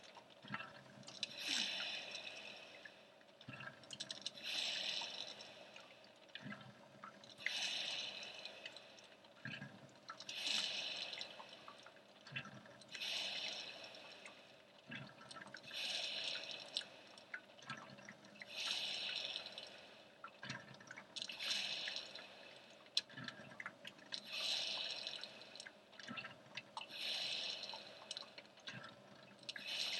Field Recording
Friday at 9am, coffee percolating.
percalator.mp3